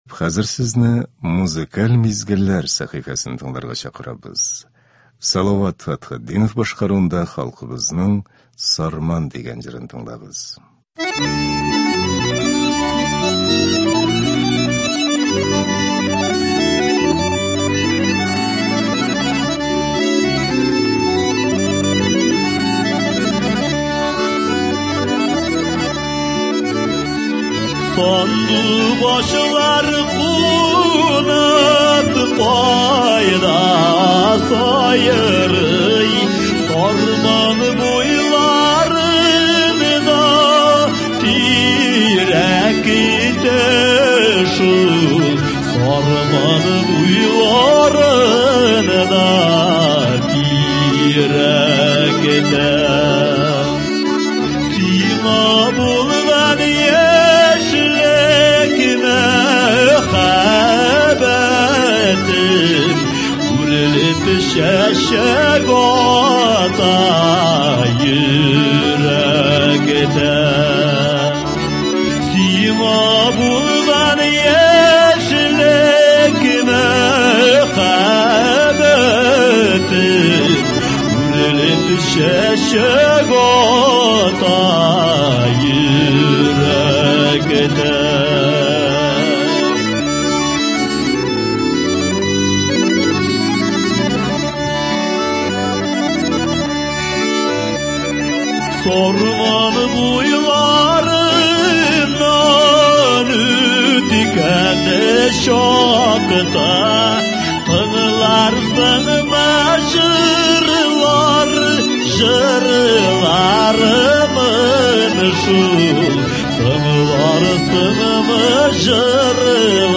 Музыкаль мизгелләр – һәр эш көнендә иртән безнең эфирда республикабыз композиторларының иң яхшы әсәрләре, халкыбызның яраткан җырлары яңгырый.